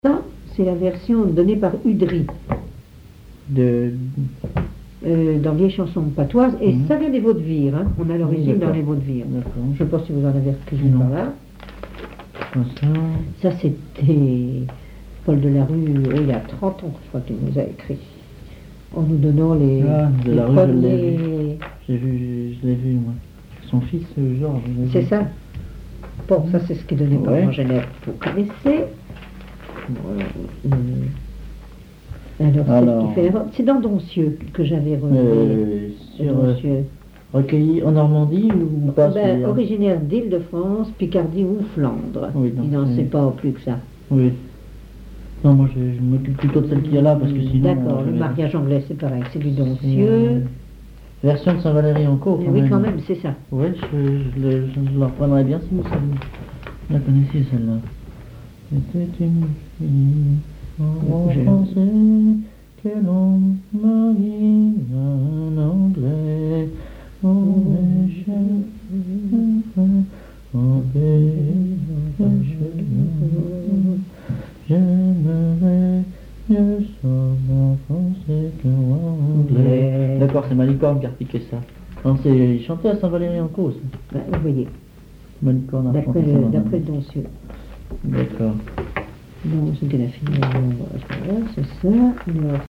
Mots Clé chanteur(s), chant, chanson, chansonnette
Catégorie Témoignage